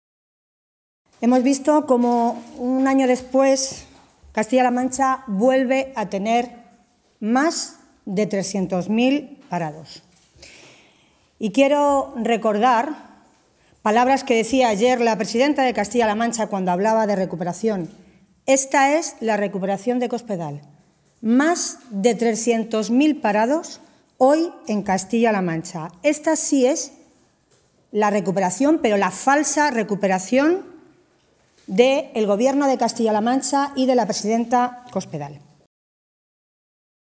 Tolón se pronunciaba de esta manera esta mañana, en Toledo, en una comparecencia ante los medios de comunicación en la que aseguraba que, después ya de tres años de legislatura, “se puede decir que Cospedal es la Presidenta de los 300.000 parados”.
Cortes de audio de la rueda de prensa